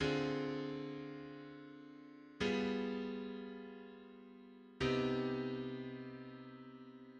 Four-voice ii–V–I in C minor: Dm75–G9–CmM7[8]